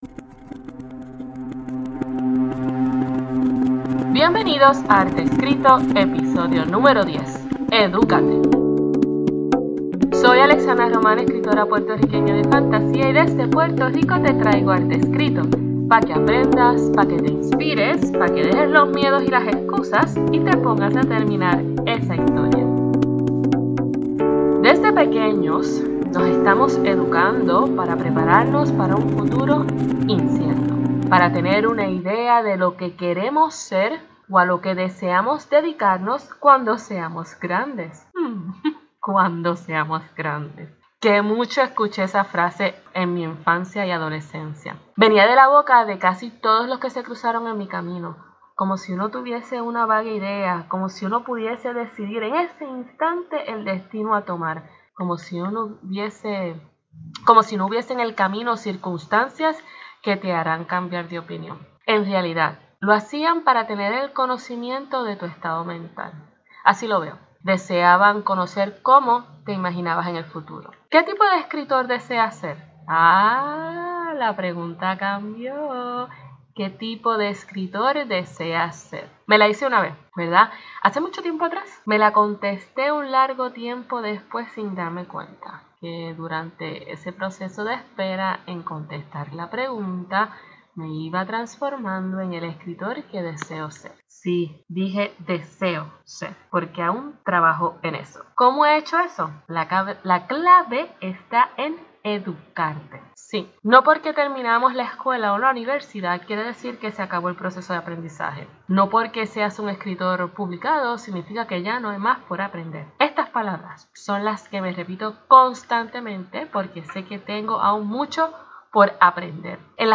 Edúcate es un episodio en el que te comparto lo que hago para mantenerme creciendo como escritora. en las notas del programa te dejo algunos enlaces a esos podcasts y vídeos que utilizo para educarme. disfruta del aguacero que se cuela en el audio al final del episodio.